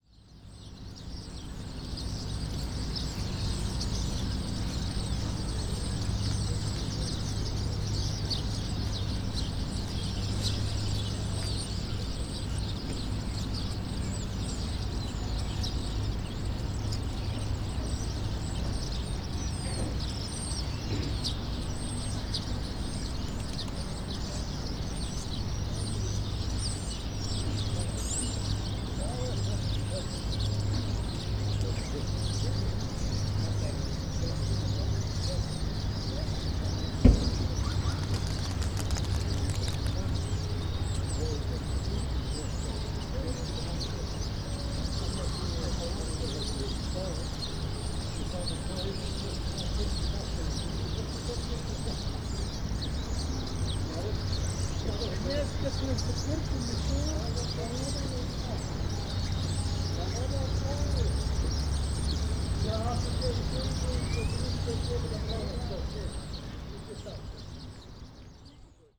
ambience
Dock Ambience - Birds, Trucks, Voices